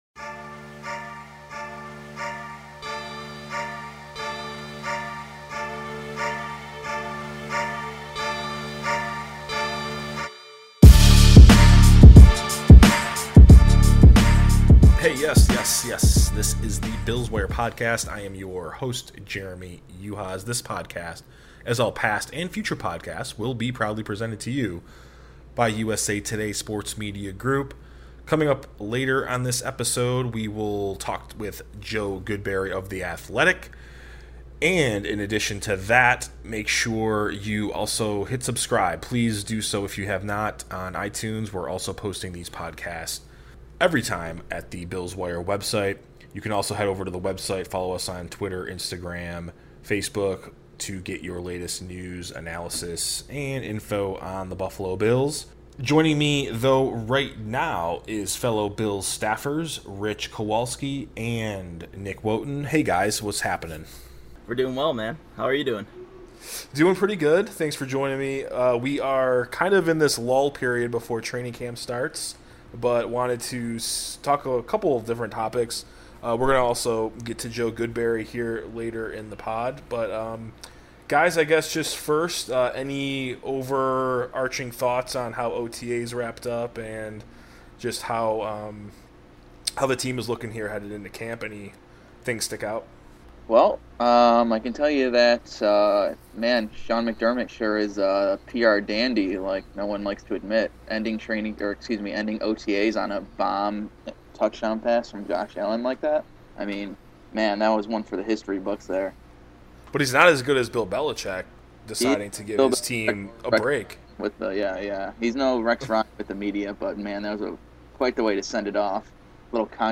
Episode 37: Bills minicamp talk, interview